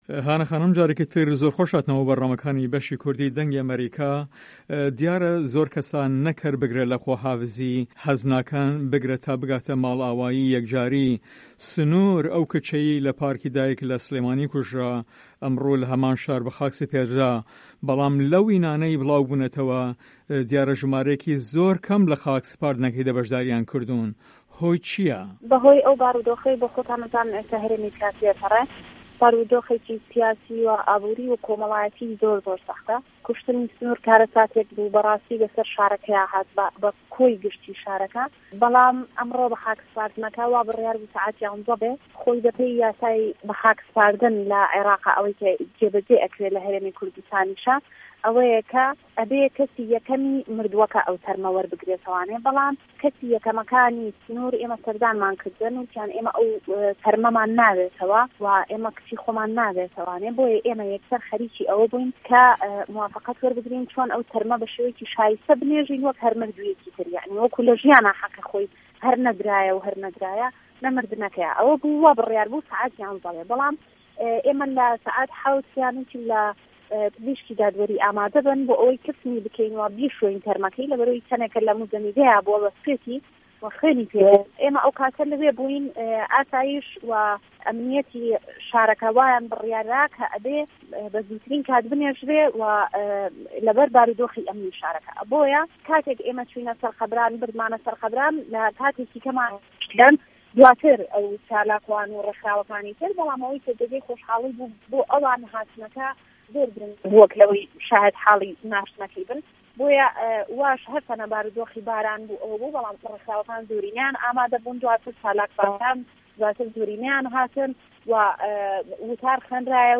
هەڤپەیڤینێکدا